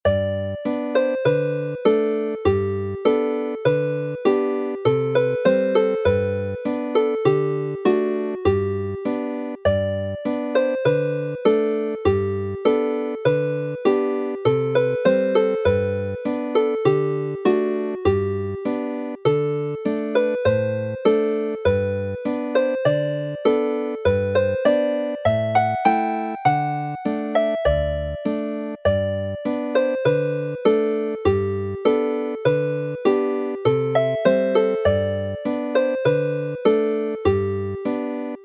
Chwarae'r alaw'n araf
Play the melody slowly